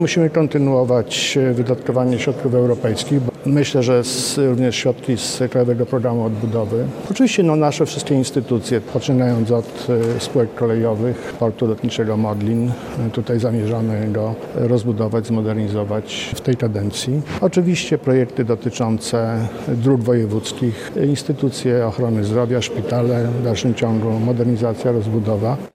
Naszym wspólnym celem na najbliższe lata jest realizacja zaplanowanych inwestycji- mówi Adam Struzik, marszałek woj. mazowieckiego: